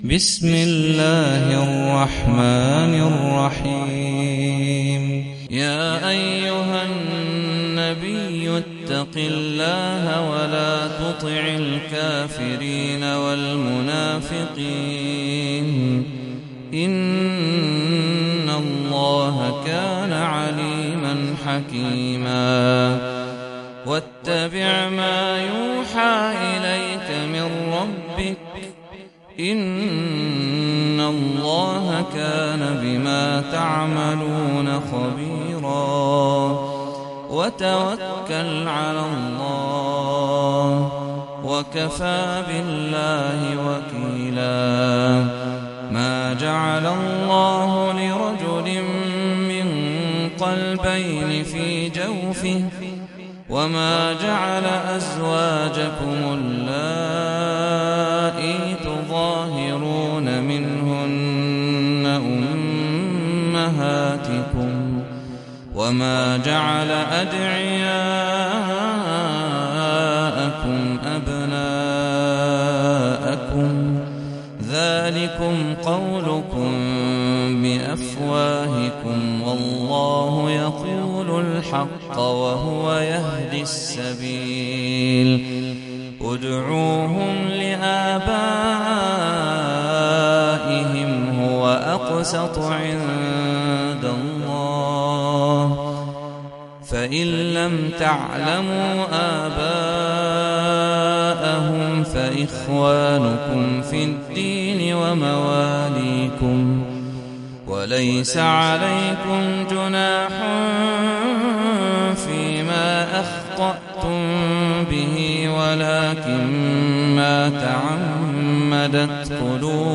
سورة الأحزاب - صلاة التراويح 1446 هـ (برواية حفص عن عاصم)
جودة عالية